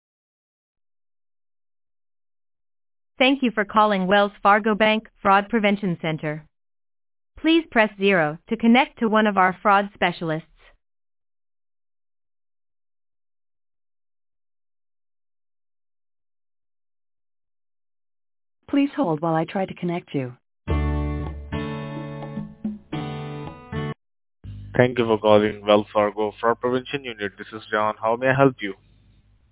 Scams Robo Calls